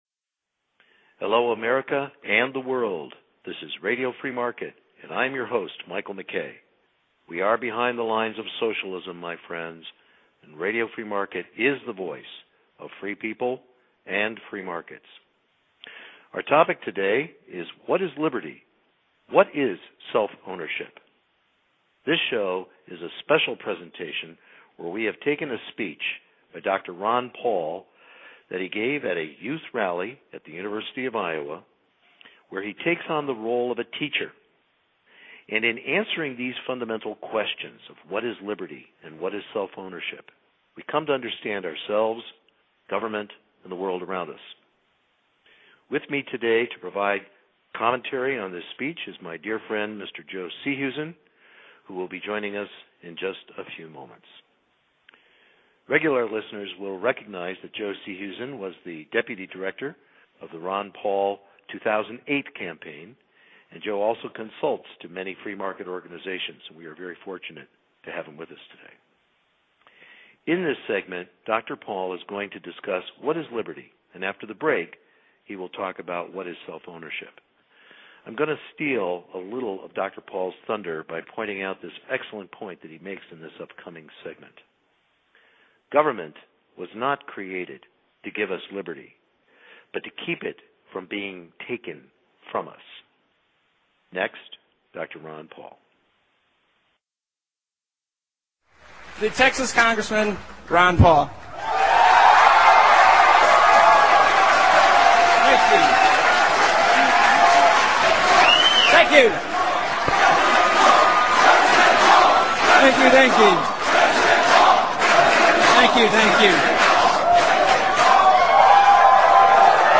A Special Presentation with Ron Paul ** In this Special Presentation we have taken a speech made by Dr. Ron Paul at the University of Iowa on October 21, 2011 where he takes on the role of Teacher.